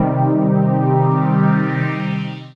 DM PAD6-8.wav